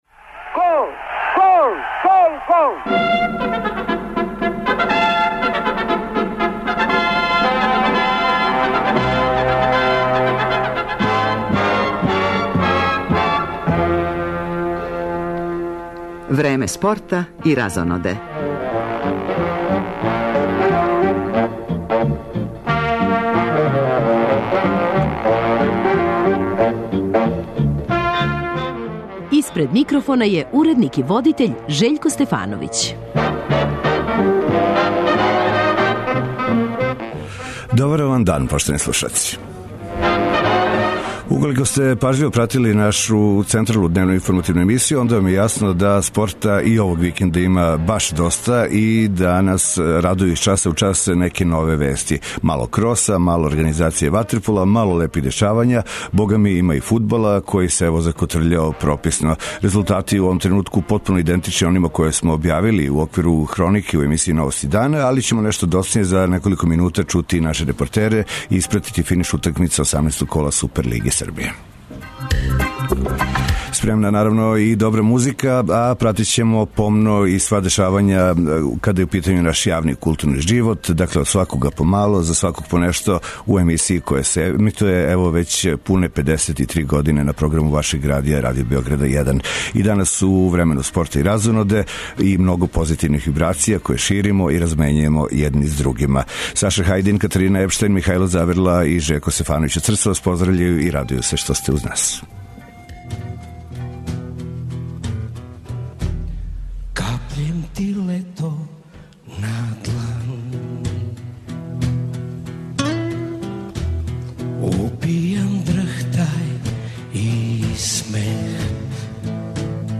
У овом издању породичног магазина Радио Београда 1 испратићемо финиш поподневних утакмице Супер лиге Србије у фудбалу, али то није све када су у питању јављања репортера.